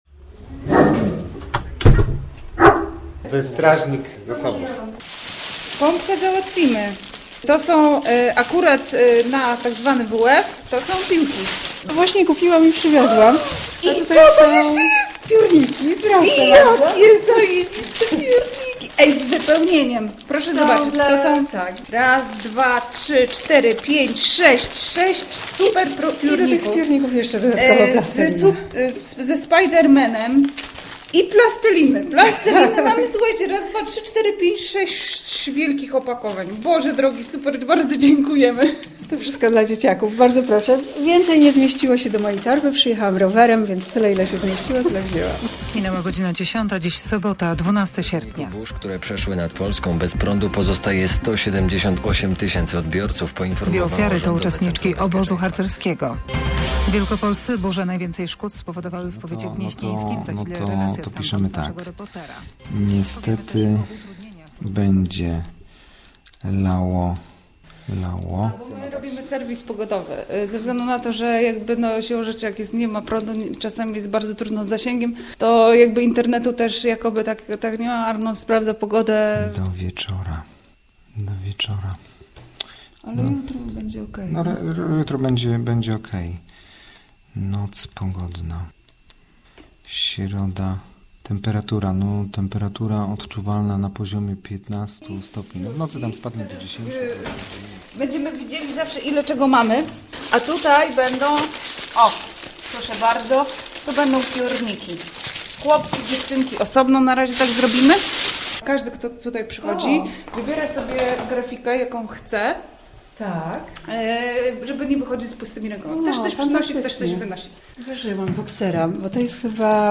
8fgldeug2t543wh_reportaz_wszystko_sie_uda.mp3